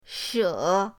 she3.mp3